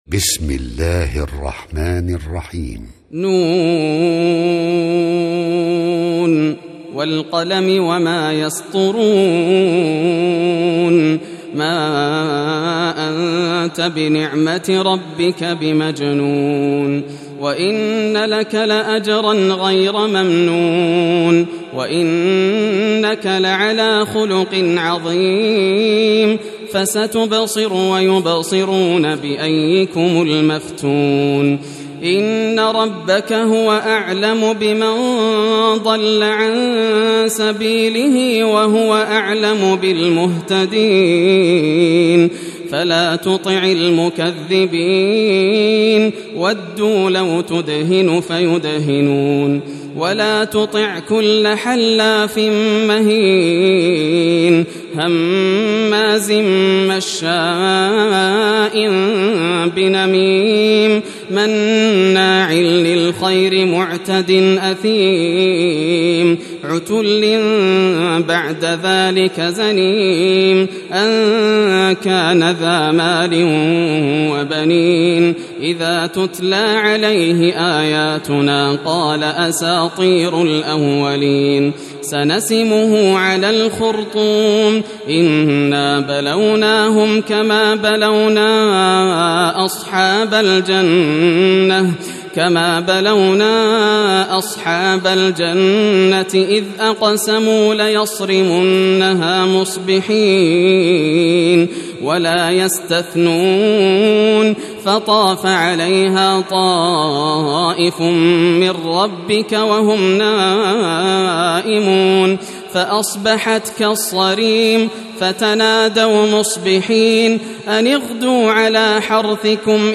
سورة القلم > المصحف المرتل للشيخ ياسر الدوسري > المصحف - تلاوات الحرمين